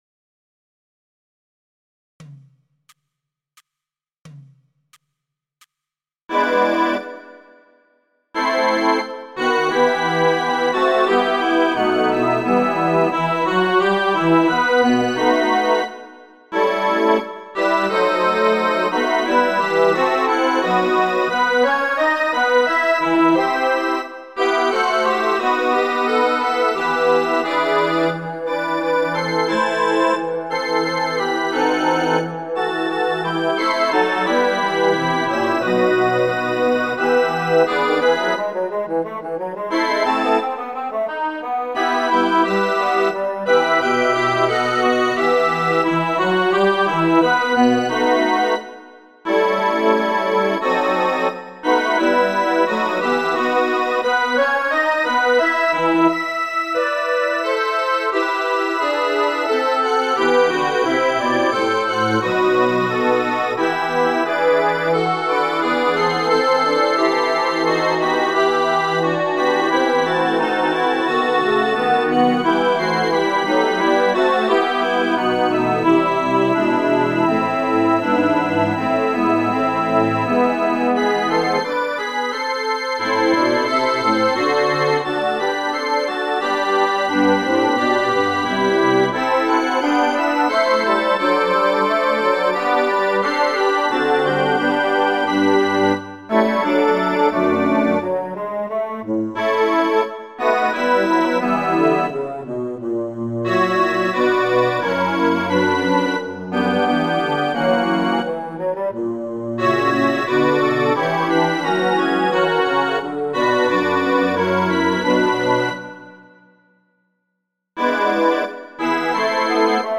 練習用音源の公開棚
左記は試聴兼観賞用のパート均等で、